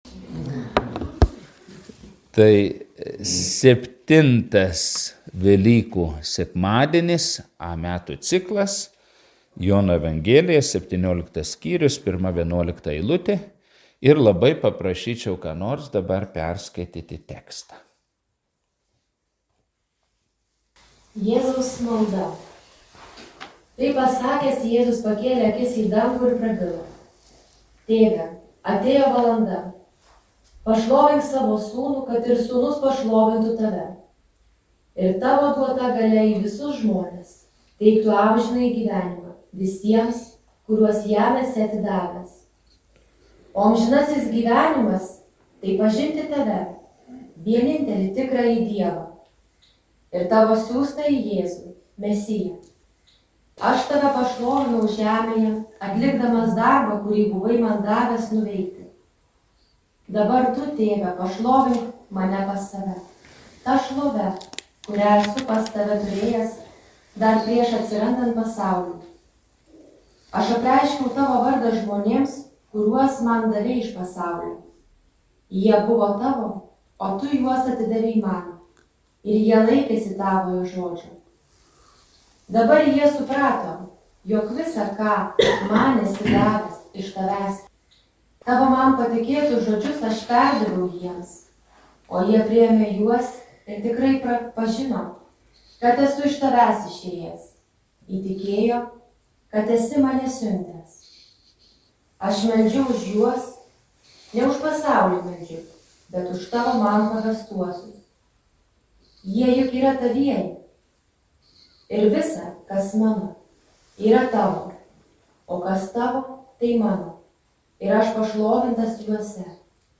Evangelijos tekstas Jn 17, 1-11 Paskaitos audioįrašas